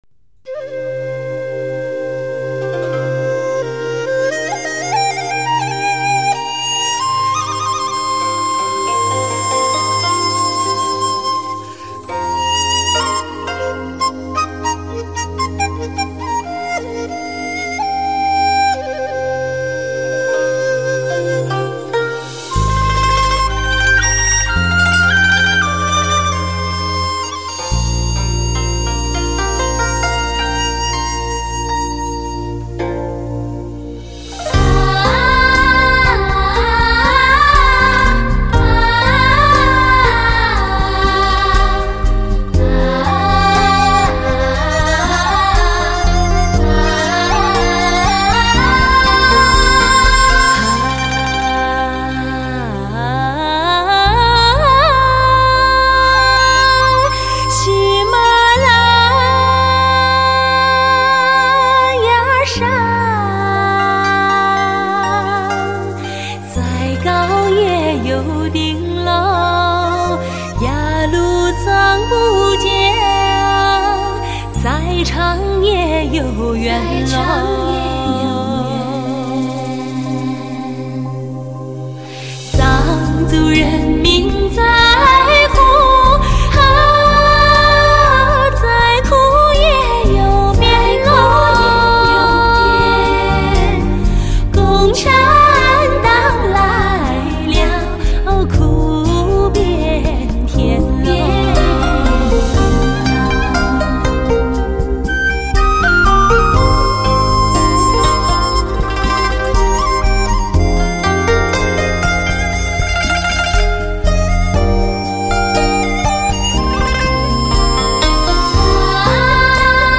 专辑资源：试音碟
新世纪发烧美丽女声，重温往日感动好时光。